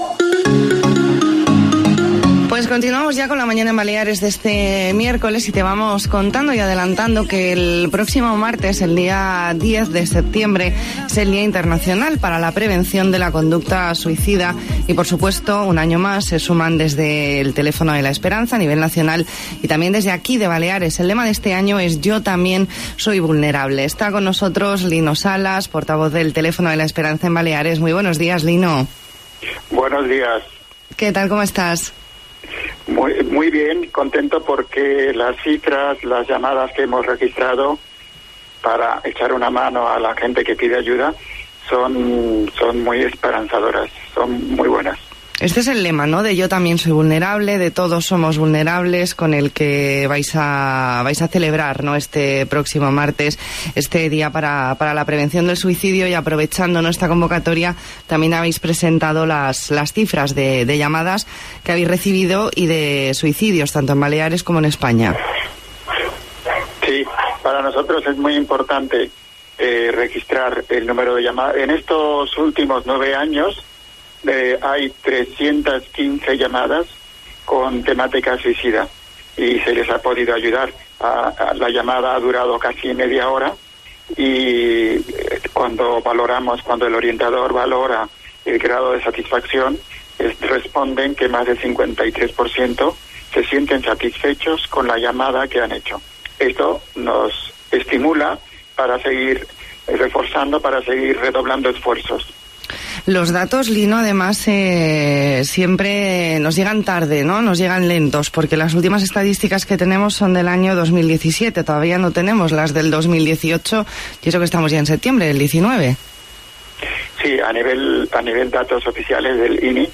Entrevista en La Mañana en COPE Más Mallorca, miércoles 4 de septiembre de 2019.